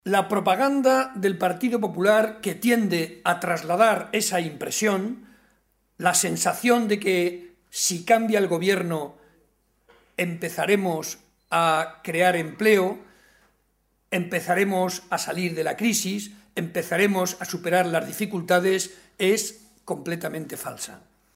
Barreda argumentó que la realidad es más complicada que las “falsas expectativas” que lanzan los responsables políticos del PP, y para comprobarlo, señaló que tan sólo hay que ver los casos de Portugal, Castilla-La Mancha o Villarta de San Juan, localidad en la que intervino en un acto público, donde se han producido esos relevos de Gobierno y “las cosas, lejos de mejorar, han ido a peo”, demostrando que la realidad es mucho más tozuda y no van a conseguir, ni mucho menos, mejorar la situación”.